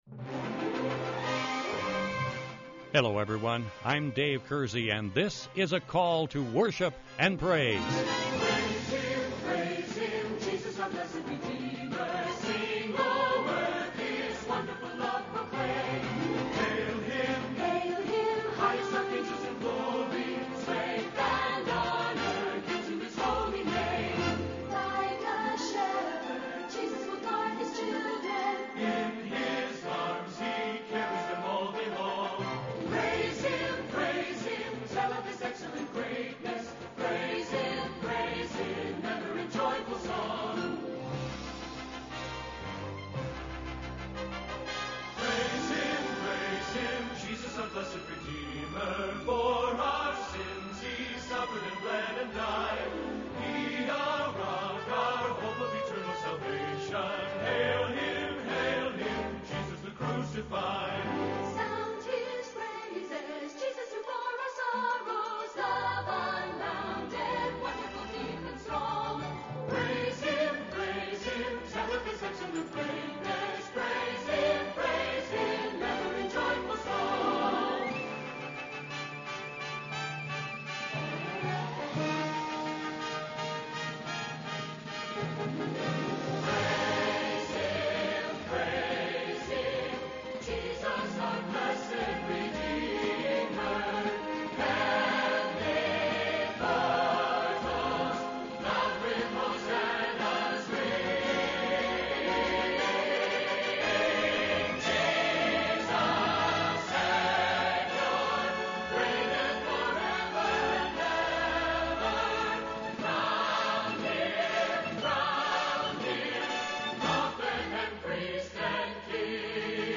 This week’s Devotional Topic This week we are singing and sharing about GOD’S GREAT LOVE for us.